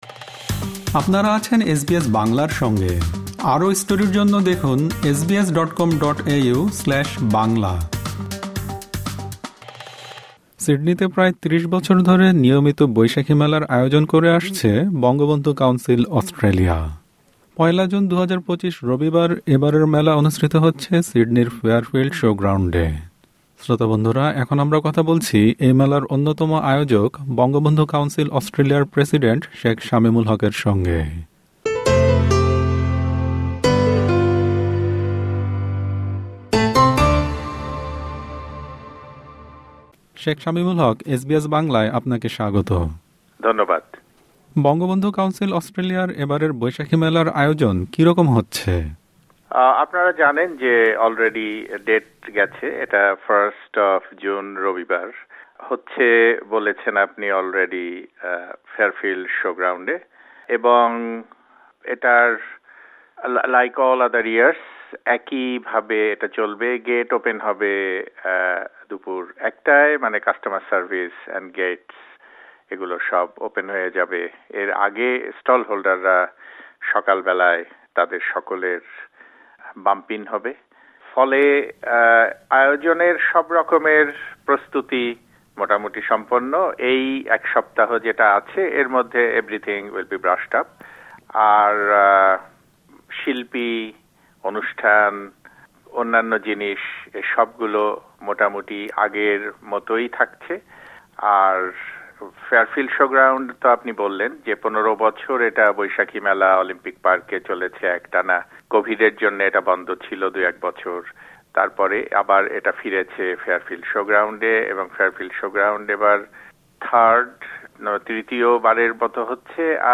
সম্পূর্ণ সাক্ষাৎকারটি